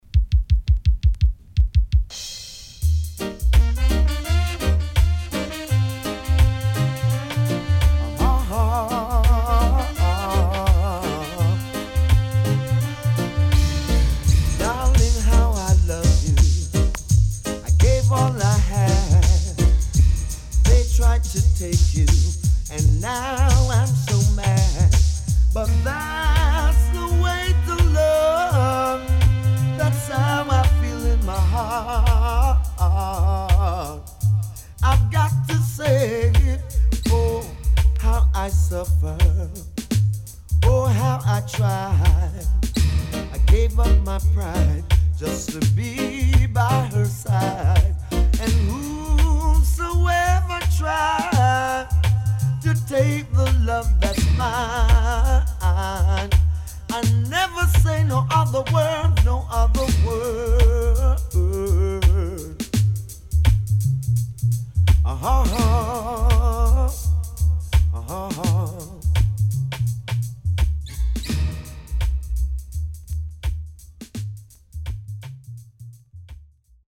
HOME > Back Order [DANCEHALL DISCO45]
SIDE A:少しノイズ入りますが良好です。